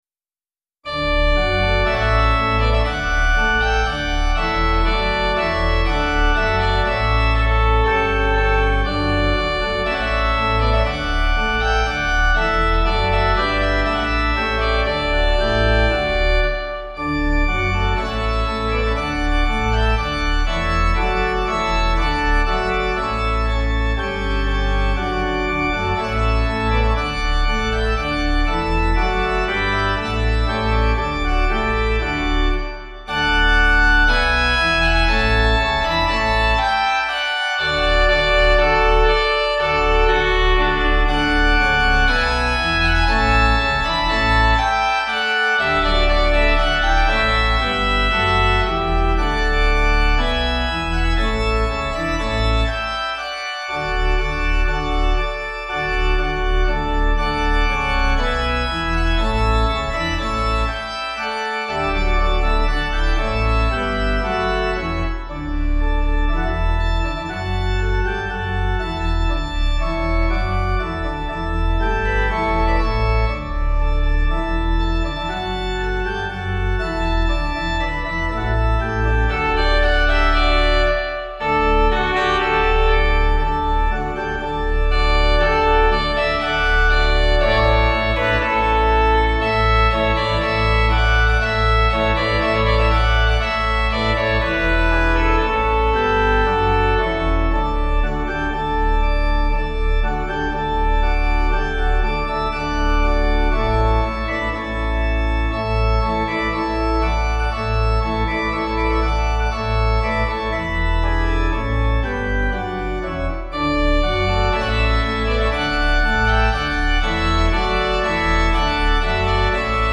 Organ
Easy Listening   1/D